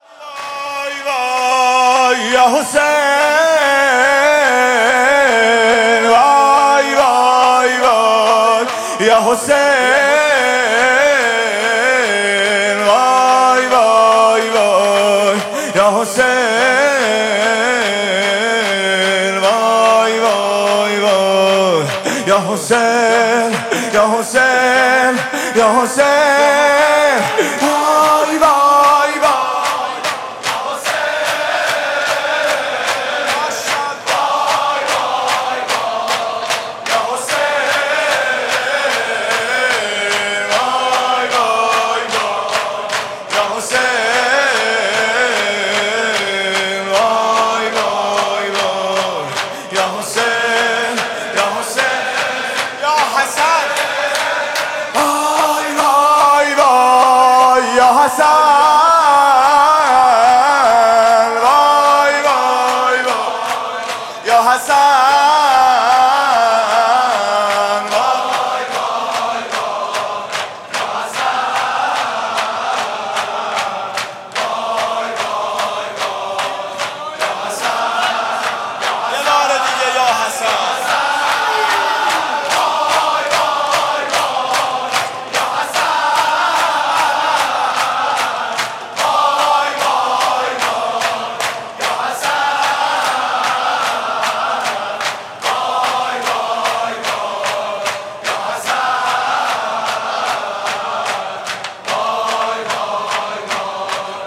music-icon شور: وای وای یا حسین